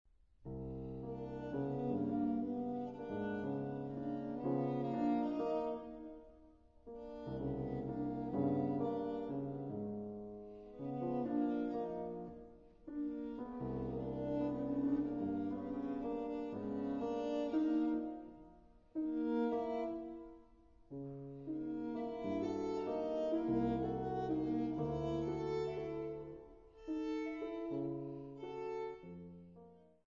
Piano
Viola
StGeorge's Brendon H., Bristol